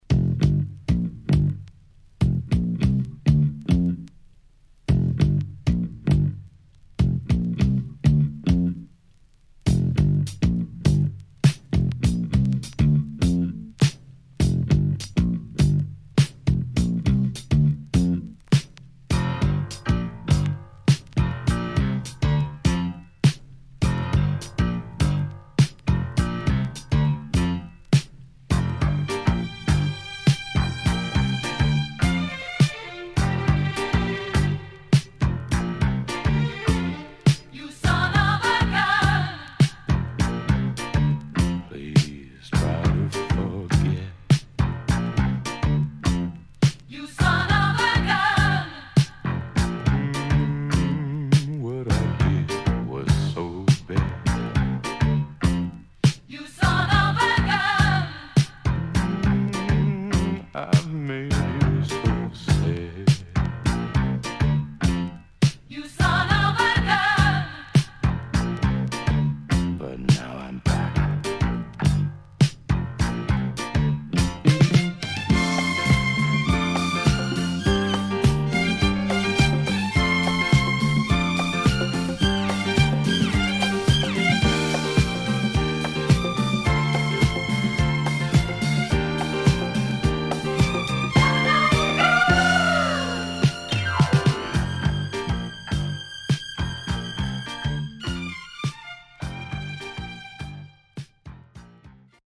HOME > Back Order [SOUL / OTHERS]